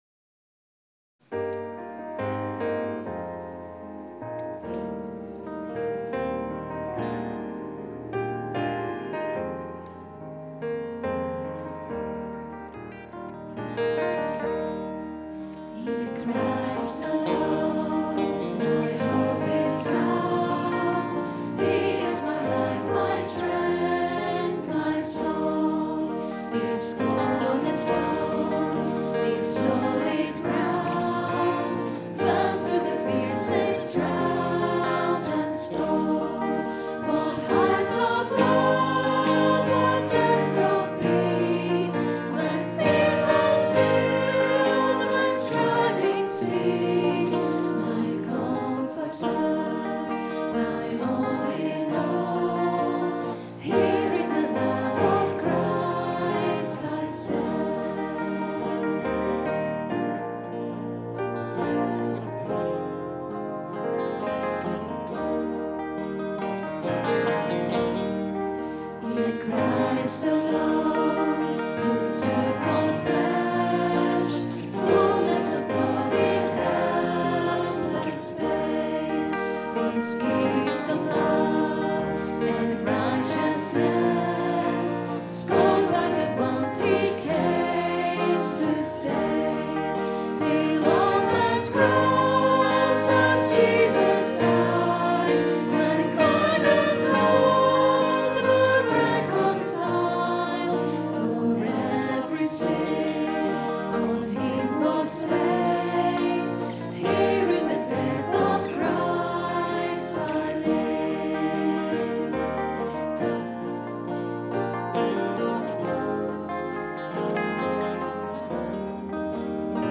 Recorded on a Zoom H4 digital stereo recorder at 10am Mass Sunday 26th June 2010.